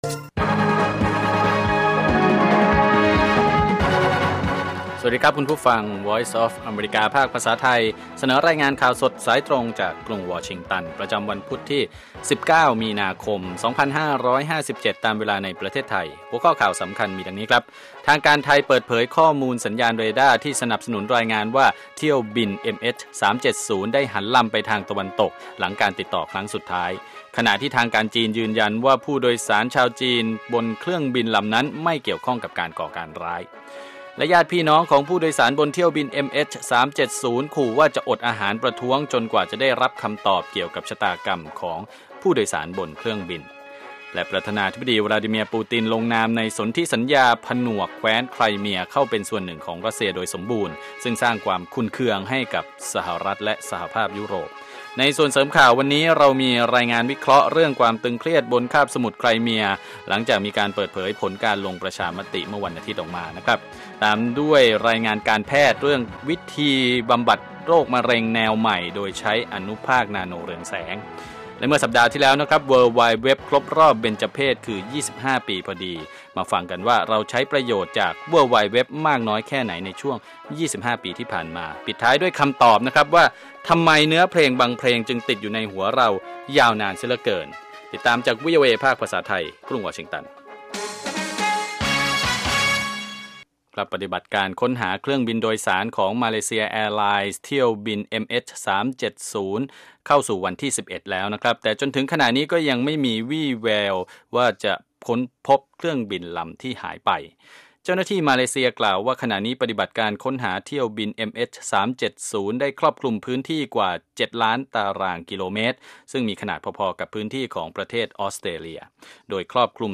ข่าวสดสายตรงจากวีโอเอ ภาคภาษาไทย 8:30–9:00 น.